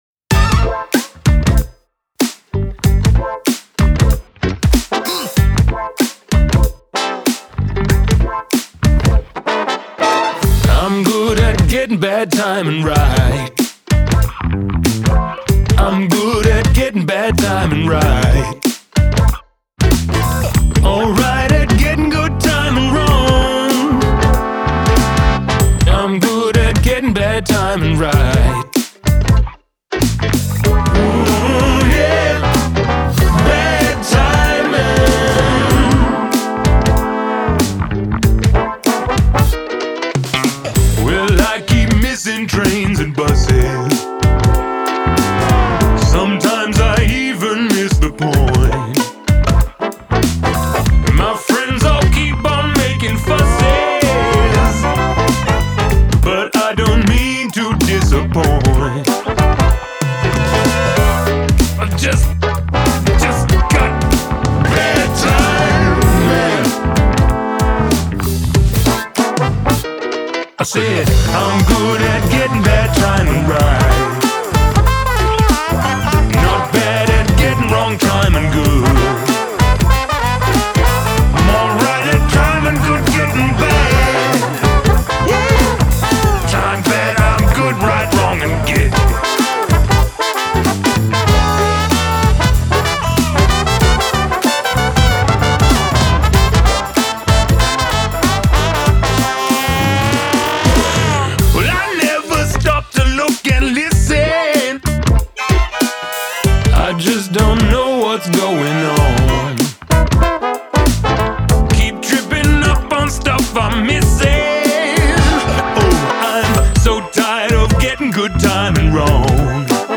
A funked-out, Bootsy-esque tune